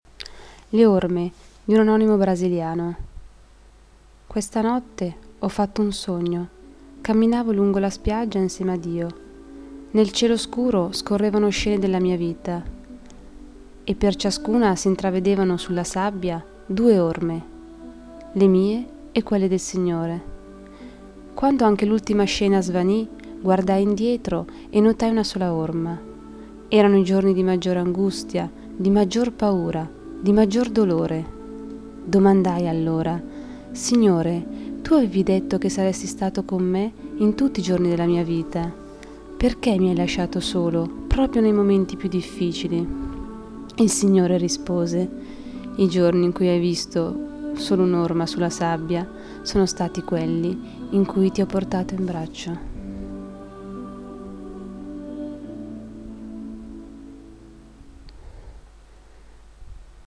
Inserito in Poesie recitate da docenti